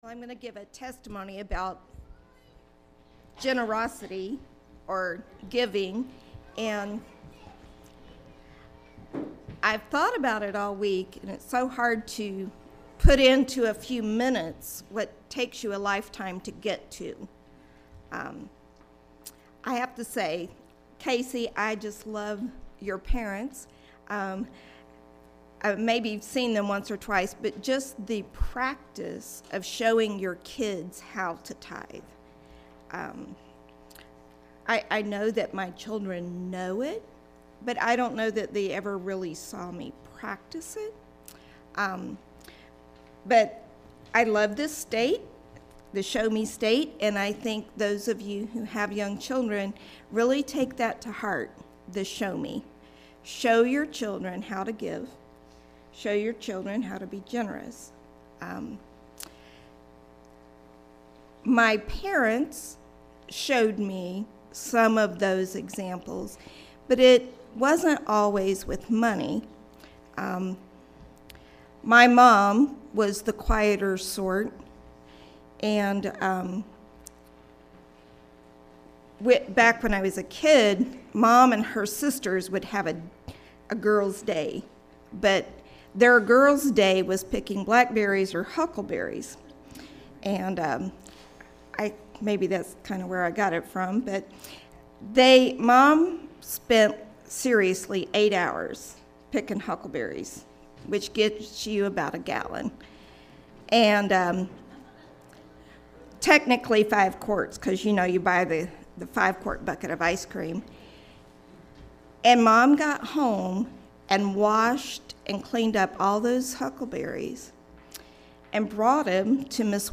Generosity Testimony